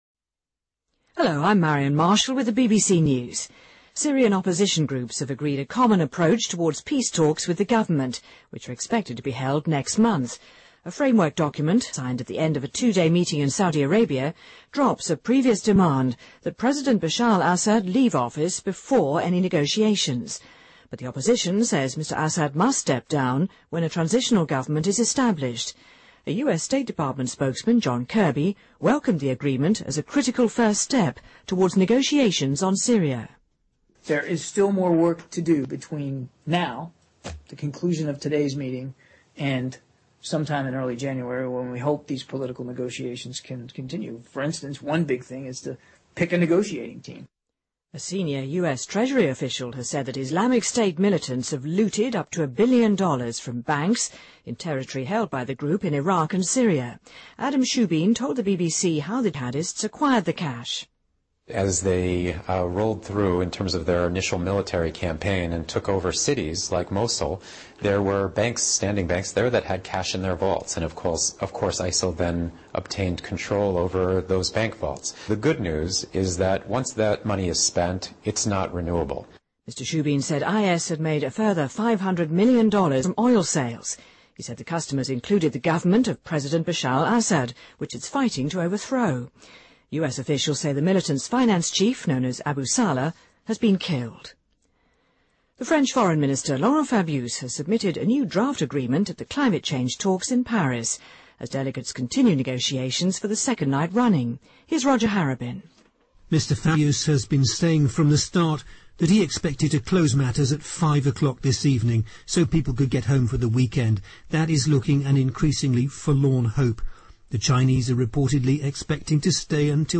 BBC news,气候变化大会形成新协议草案